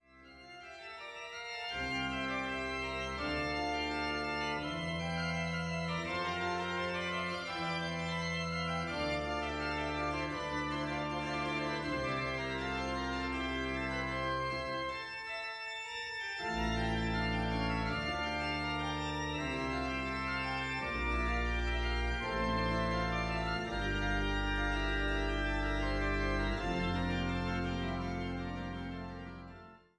Fraureuth